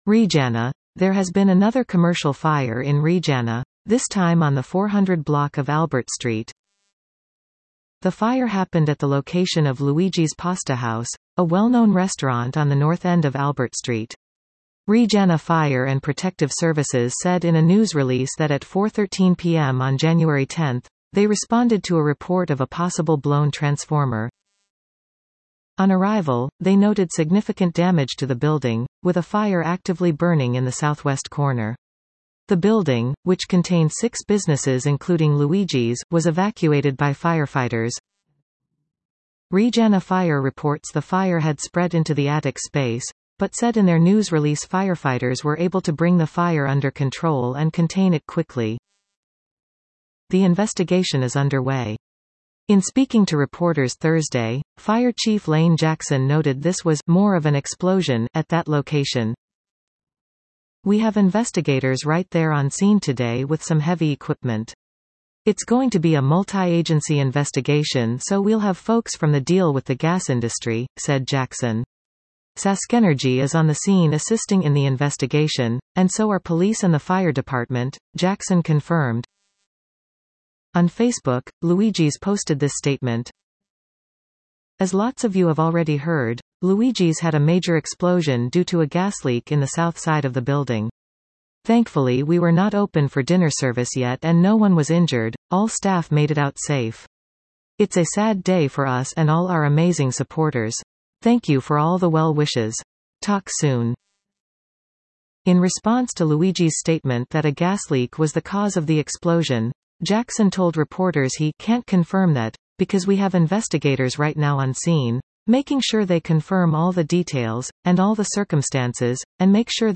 Courtesy Regina Fire and Protective Services Listen to this article 00:03:08 REGINA - There has been another commercial fire in Regina, this time on the 400 block of Albert Street.